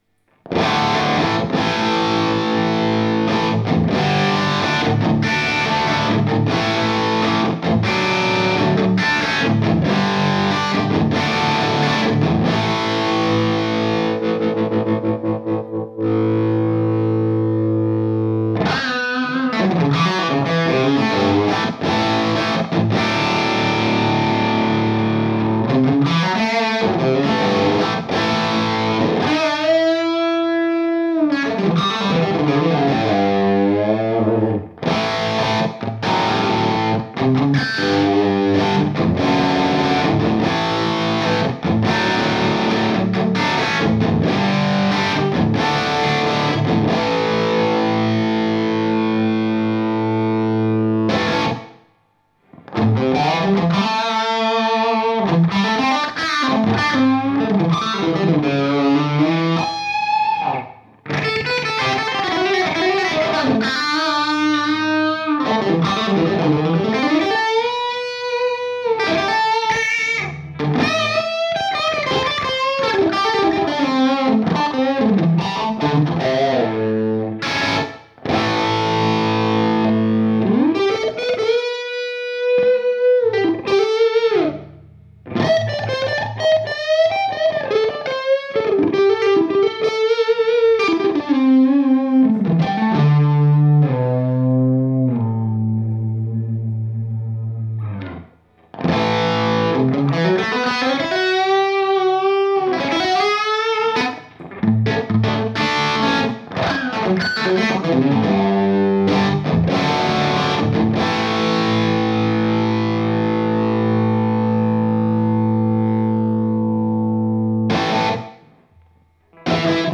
Je suis en train de faire du reamp